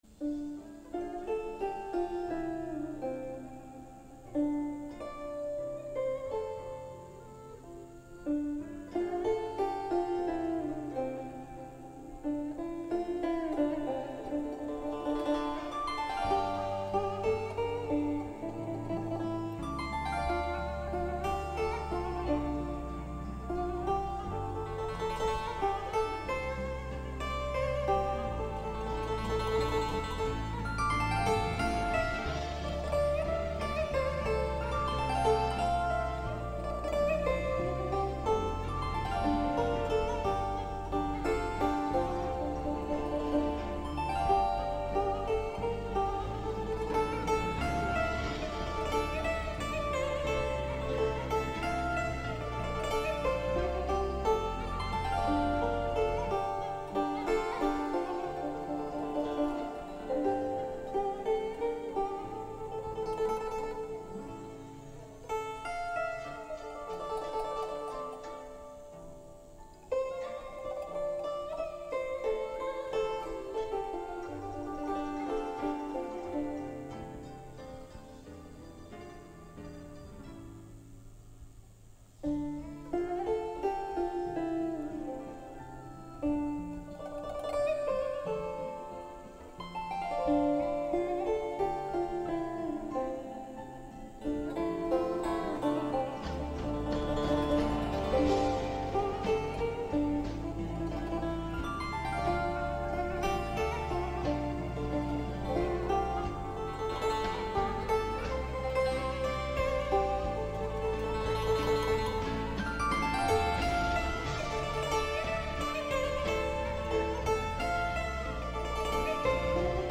Đàn Tranh cổ phong, thanh tao và sâu lắng.